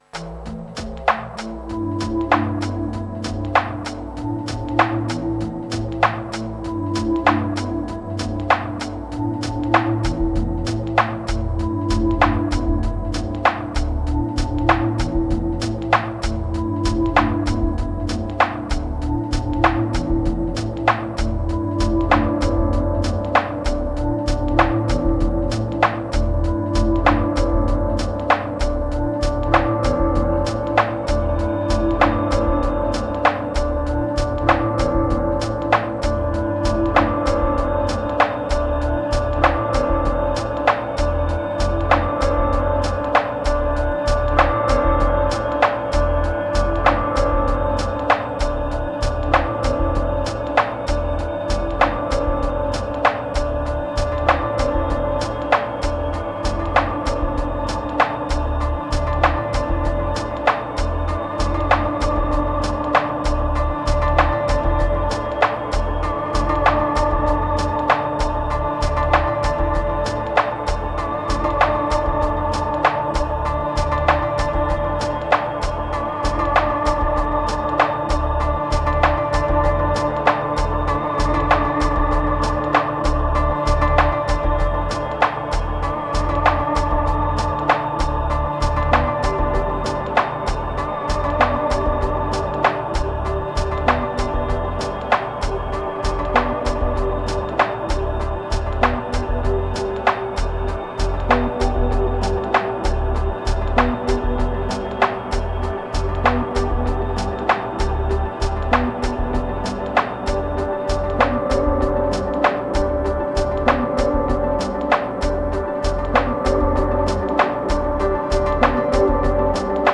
1589📈 - 95%🤔 - 97BPM🔊 - 2024-03-29📅 - 529🌟
One session, quite fast.
Ambient Beats Deep Ladder Hypnotic Future Moods Cinematics